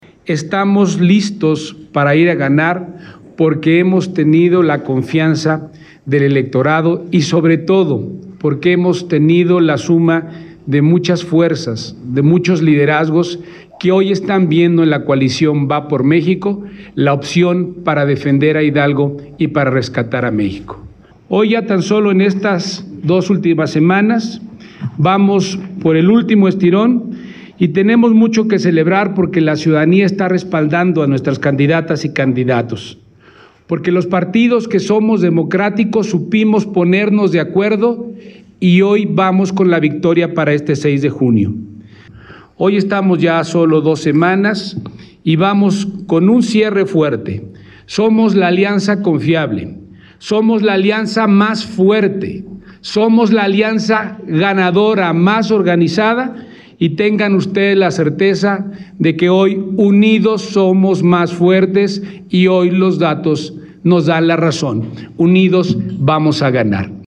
Conferencia-de-prensa-en-Huichapan.mp3